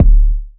subz_kick.wav